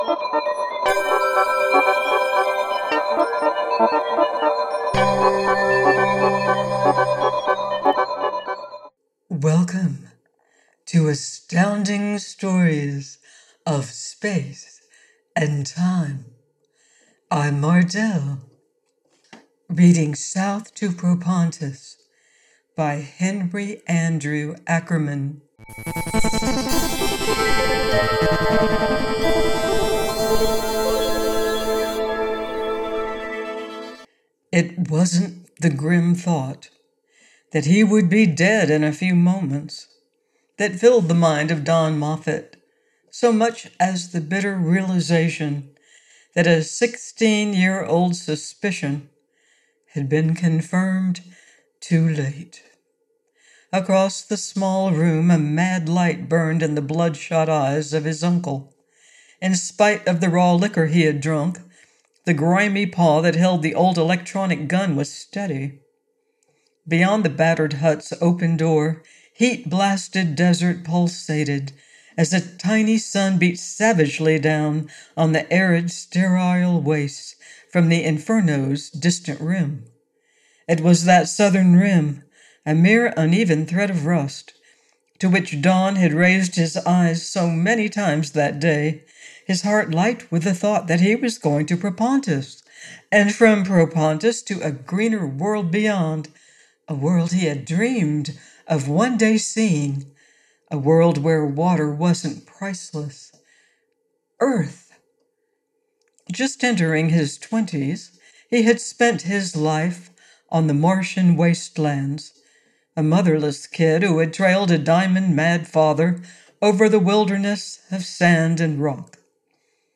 South to Propontis by Henry Andrew Ackerman - AUDIOBOOK